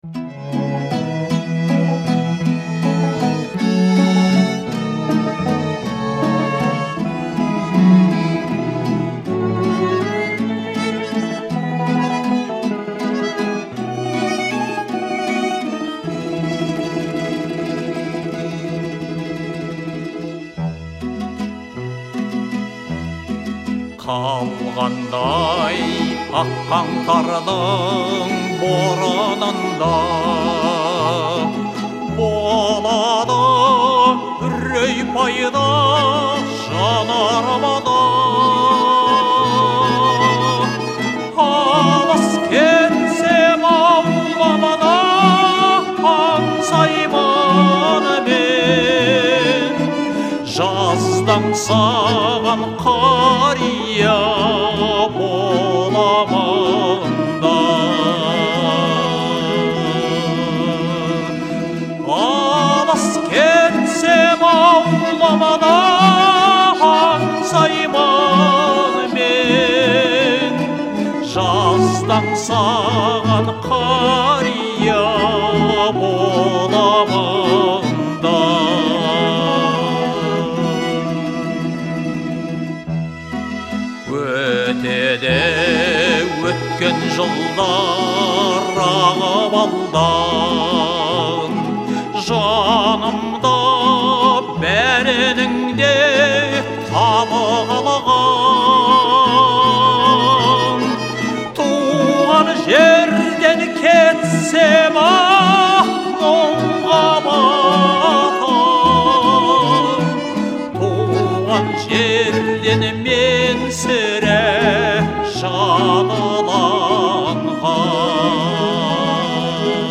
это трогательная песня в жанре народной музыки